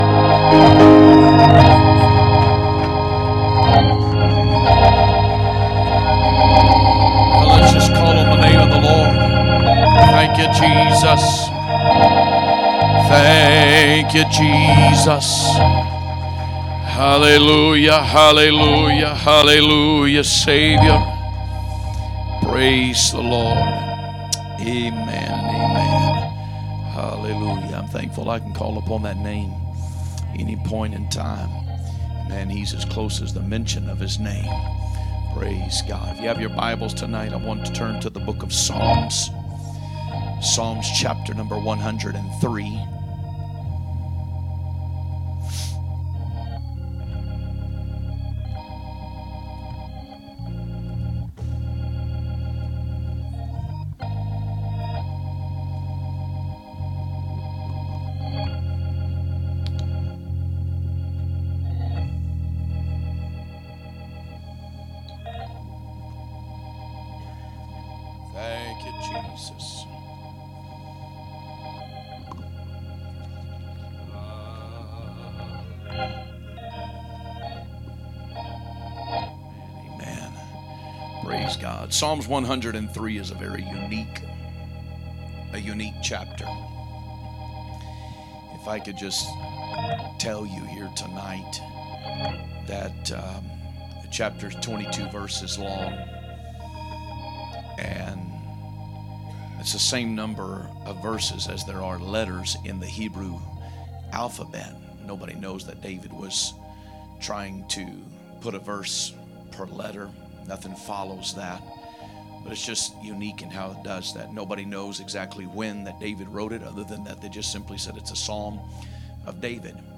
Sunday Evening Preaching